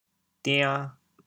调: 抵 国际音标 [tĩã]